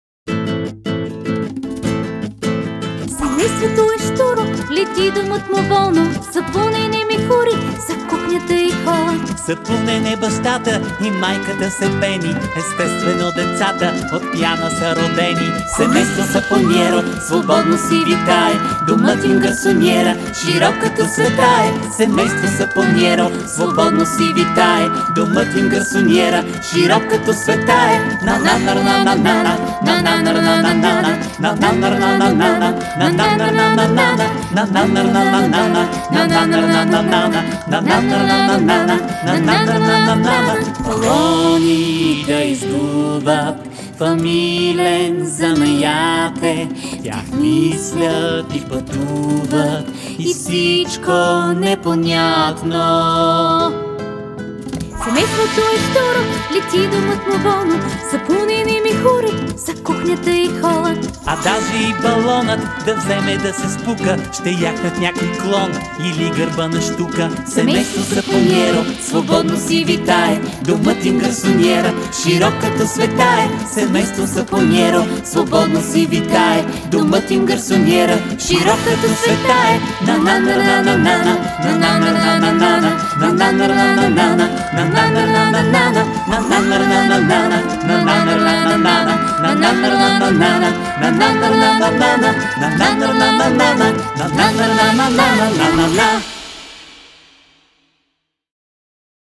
20 авторски детски песнички